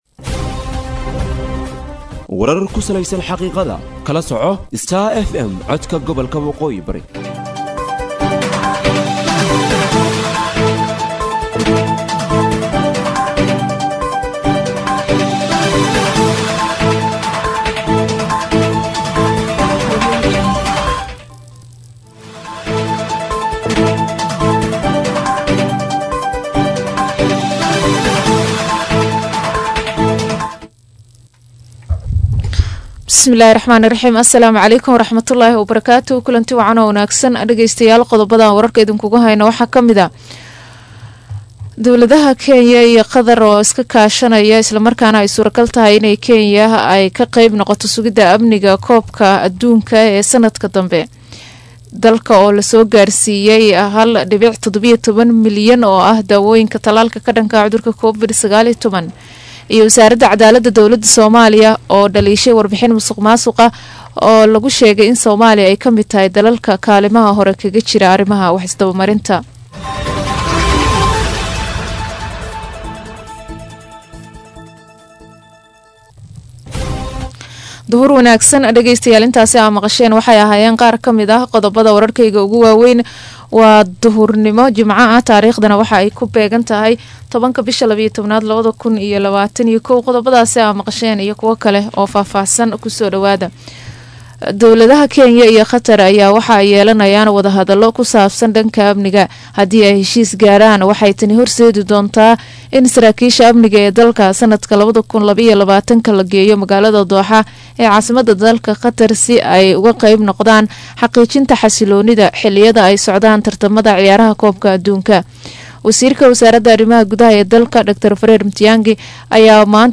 DHAGEYSO:DHAGEYSO:WARKA DUHURNIMO EE IDAACADDA STAR FM